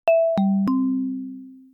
notification2.wav